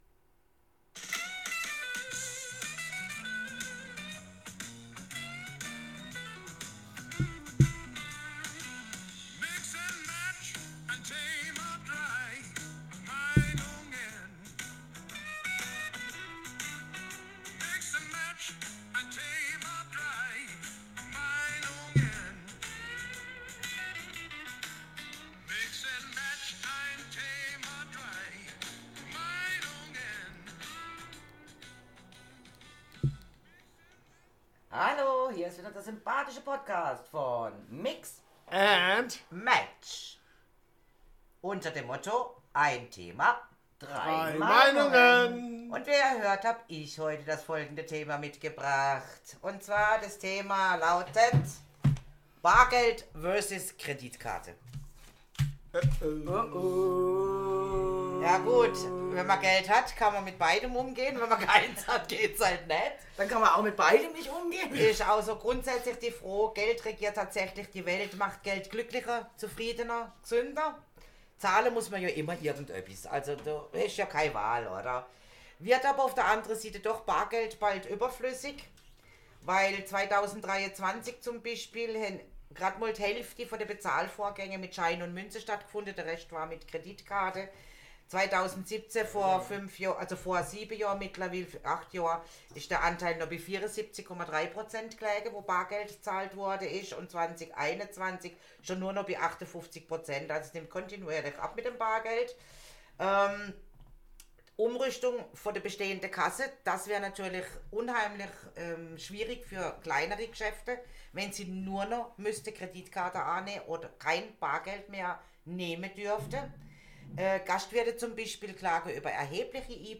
Was sind die Risiken oder die Vorteile? Wir diskutieren, wie immer unprofessionell und schweifen in andere Themen ab...